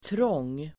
Uttal: [trång:]